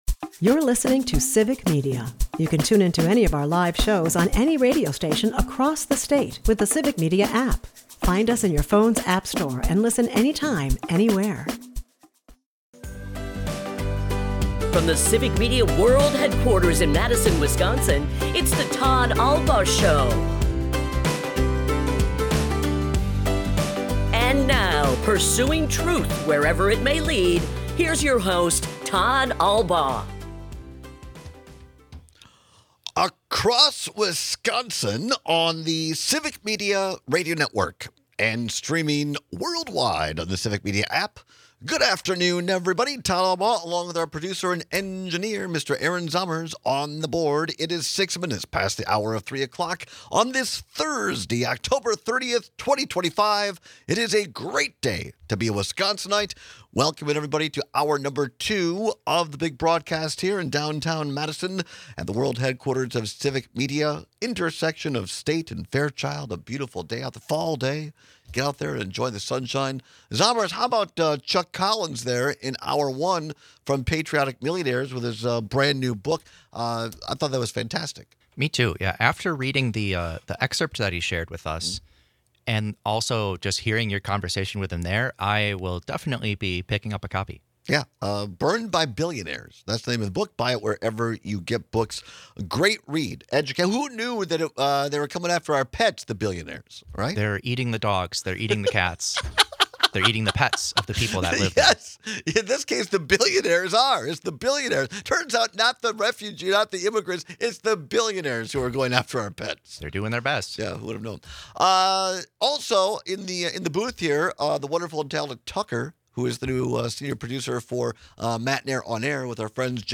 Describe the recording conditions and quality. We take calls and texts with some of your favorite horror stories. Recently in California, the Marines fired live rounds over an interstate that detonated prematurely.